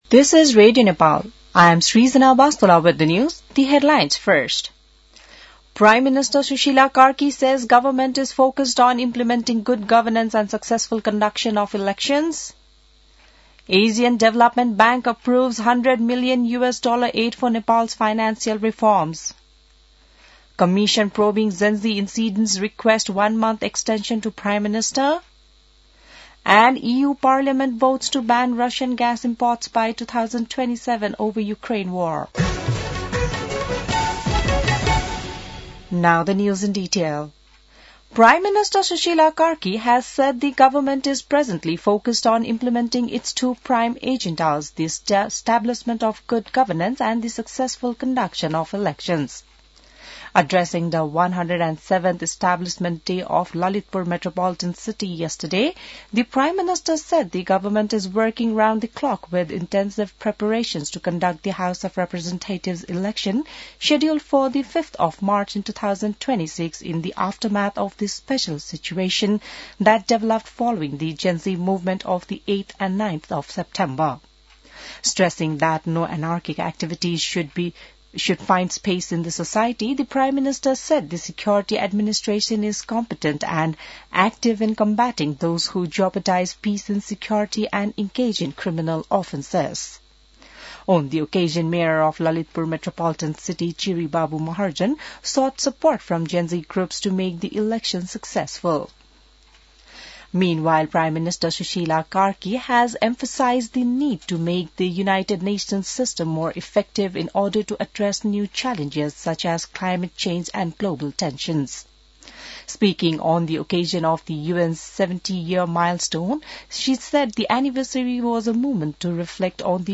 बिहान ८ बजेको अङ्ग्रेजी समाचार : ३ पुष , २०८२